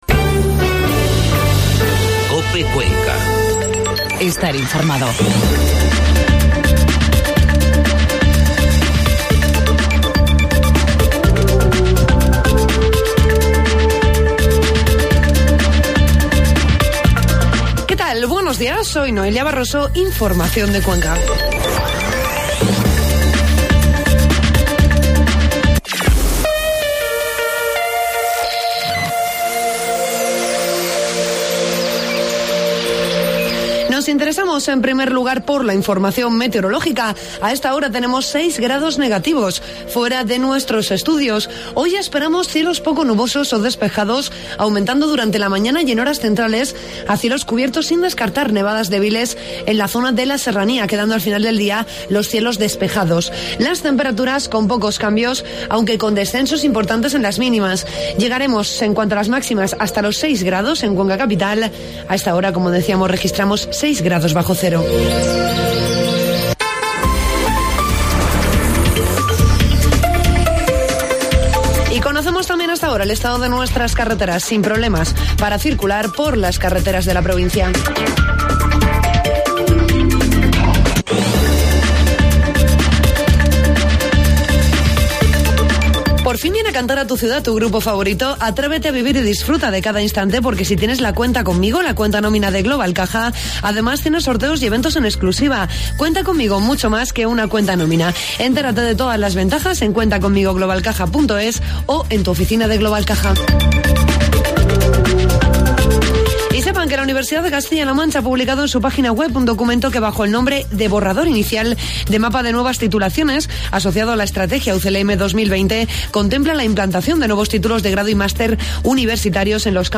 Informativo matinal COPE Cuenca 8 de febrero